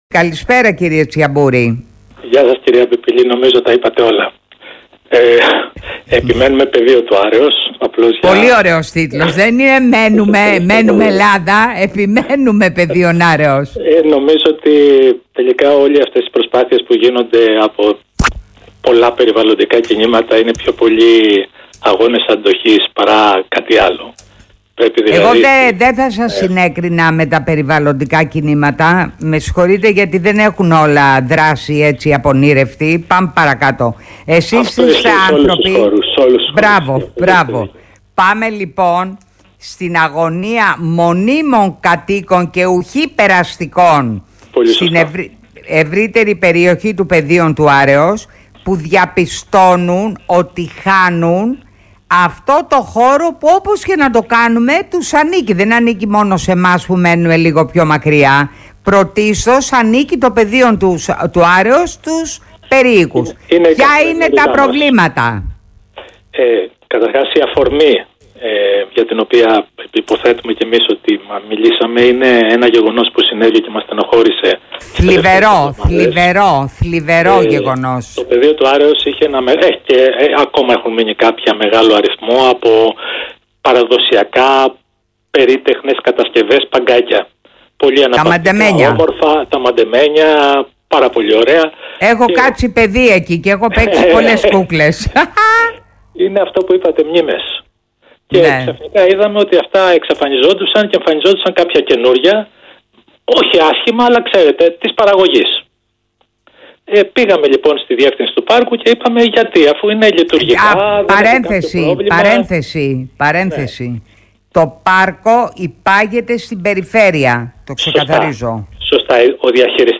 Δώσαμε συνέντευξη στην Φωτεινή Πιπιλή στο Alpha Radio 98,9 για το θέμα.